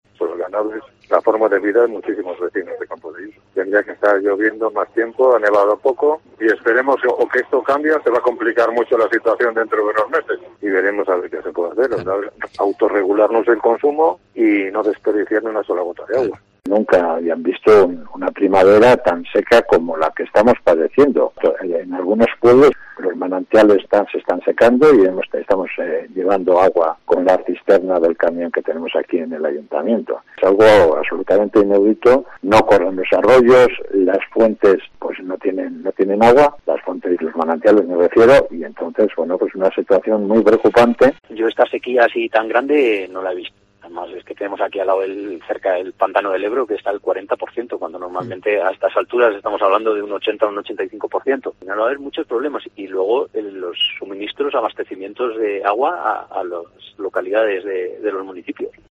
Alcaldes de Campoo y Valderredible advierten de la falta de agua y de los riesgos que hay para el suministro tanto animal como para los vecinos
Alcaldes del sur de Cantabria lamentan la falta de agua